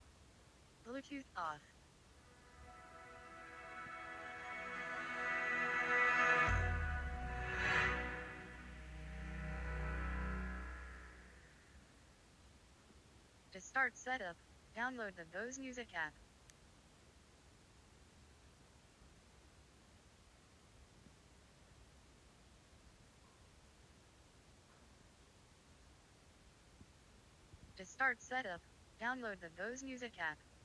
2. A female voice announces "Bluetooth off", followed by a loud startup jangle. She then advises, "To start setup, download the Bose Music app", repeating the prompt ~12 seconds later, presumably for the benefit of those temporarily deafend by the clangor.
🔈 (Voice prompts can be disabled in the Bose app, though the ear-piercing startup chime cannot.)
bqc-clangor.m4a